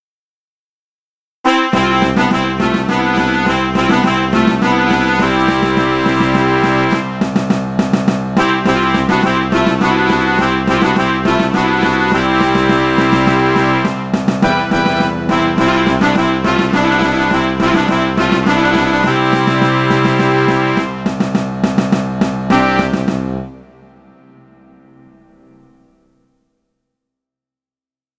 (In orchestration. No video.)